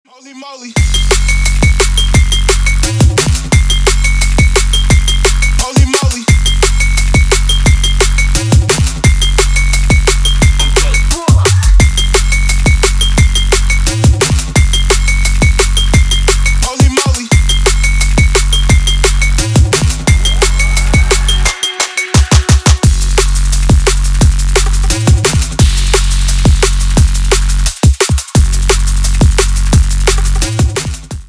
Drum and Bass рингтоны 2024
играет DubStep / DnB рингтоны🎙